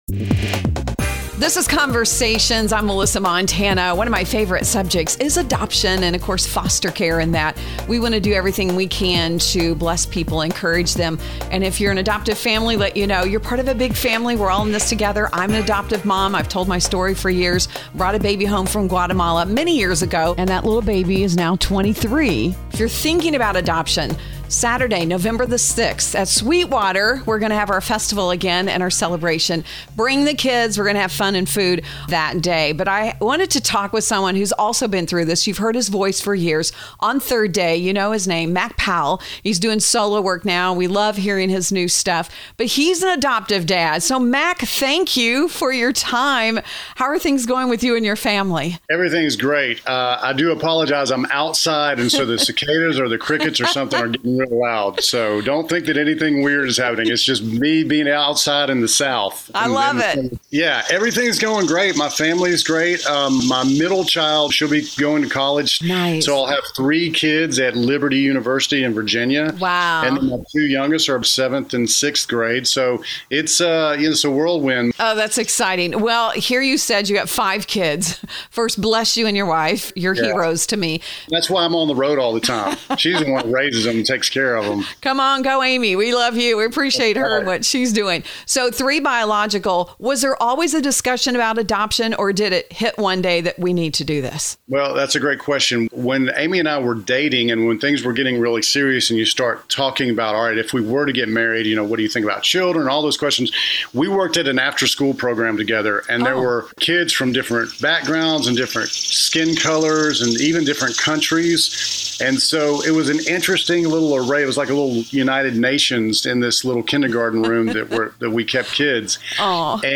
Mac Powell, Christian music artist, joins us on conversations today to share his families story of Adoption. He talks about the ups and downs they have experienced on their adoption journey and how it has changed their life for the better.
For other interviews covering several different topics